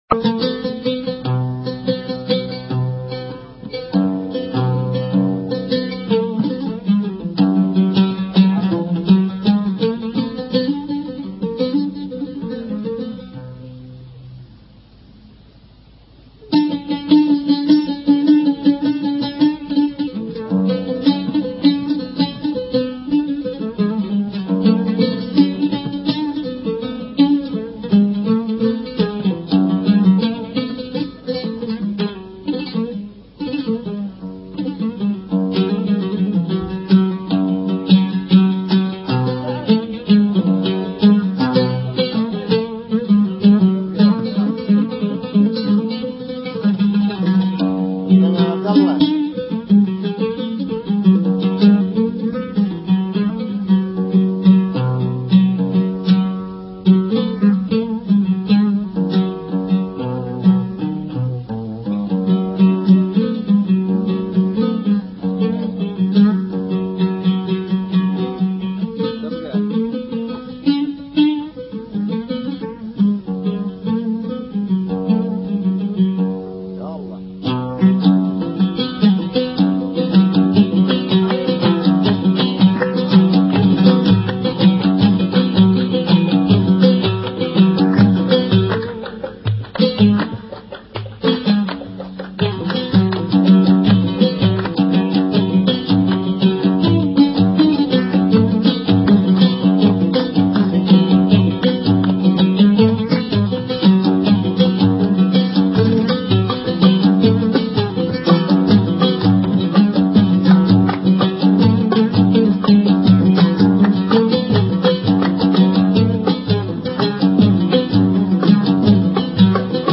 يغني في إحدى جلساته